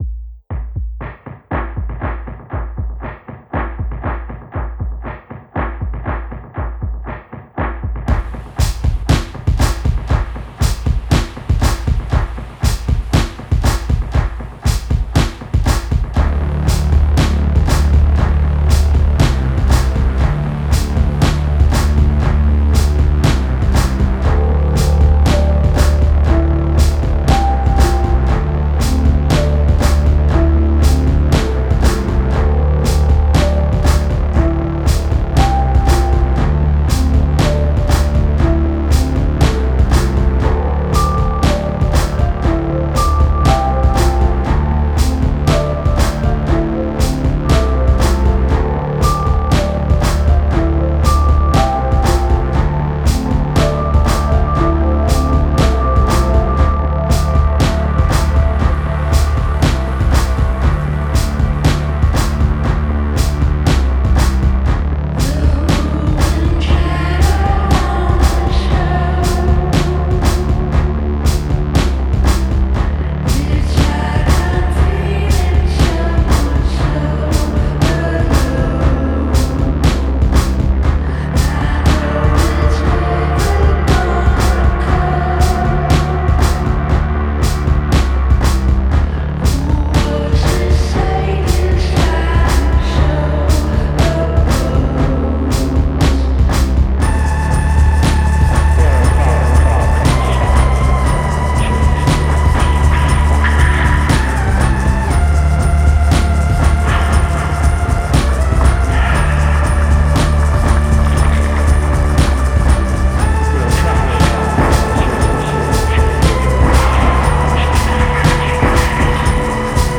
Жанр: Experimental.